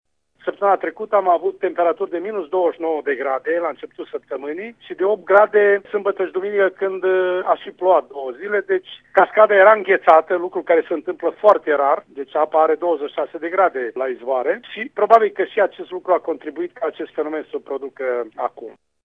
Primarul municipiului, Stelu Platon, a declarat, pentru RTM,  că incidentul s-ar fi produs din cauza diferenţelor mari de temperatură înregistrate săptămâna trecută: